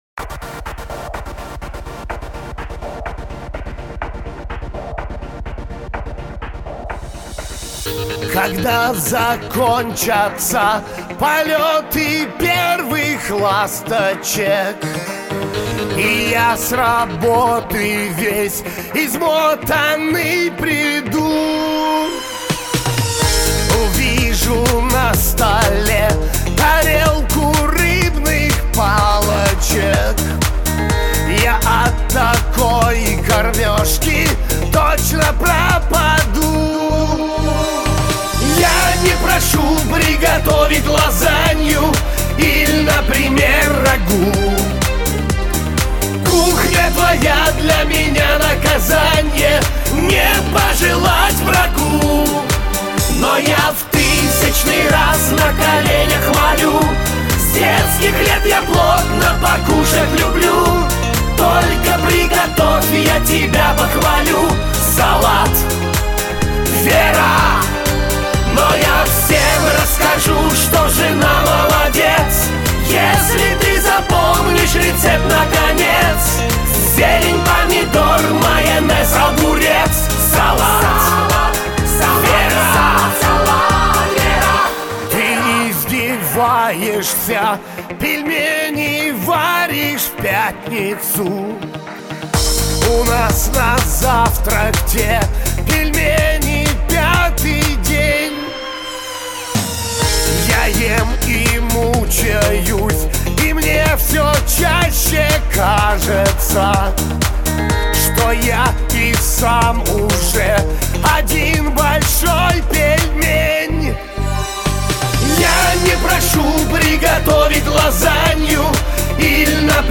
ПІСЕНЬКУ ЖАРТІВЛИВУ